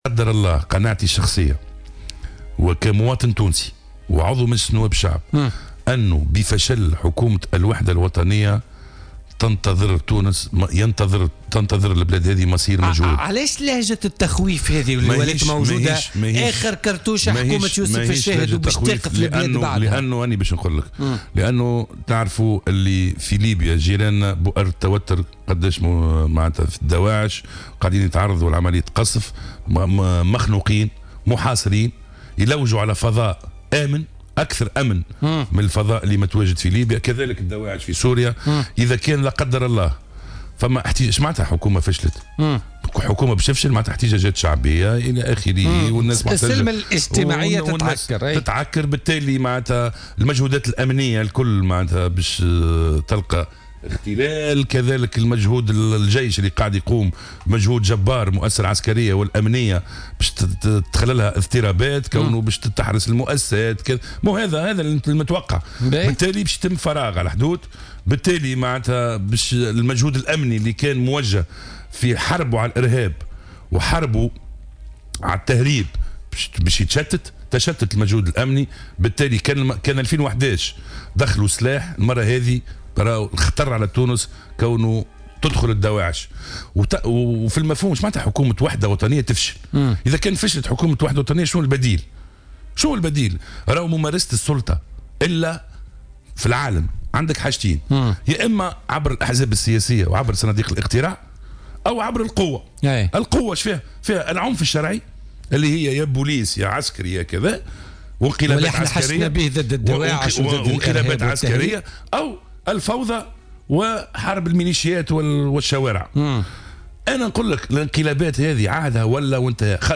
وأضاف جلاد في مداخلة له اليوم في برنامج "بوليتيكا" أن عدم الاستقرار الاجتماعي سيؤدي إلى إضعاف مجهودات المؤسستين العسكرية والأمنية و تشتيتها و خلق فراغ على الحدود، مما سيشكل خطرا كبيرا على تونس، خاصة بعد تضييق الخناق على الجماعات الإرهابية في ليبيا و في سوريا والتي أصبحت تبحث عن أماكن أكثر أمانا، وفق تعبيره.